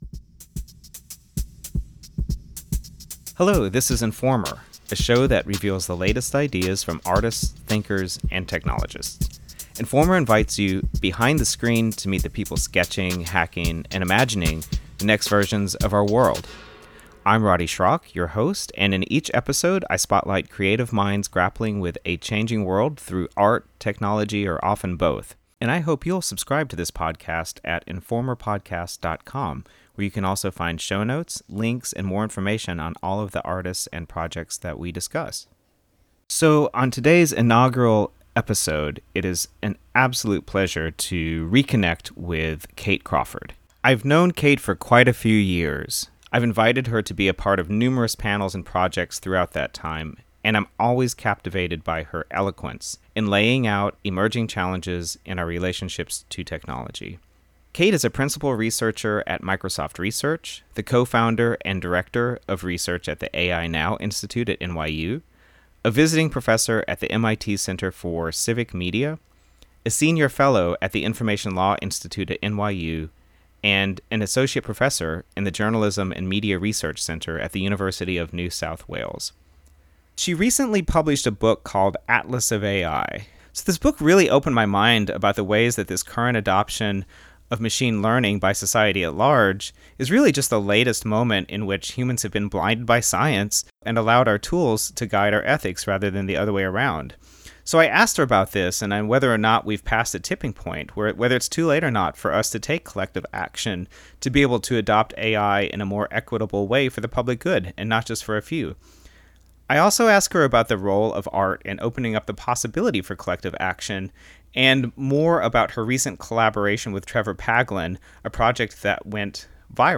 A conversation with AI researcher Kate Crawford.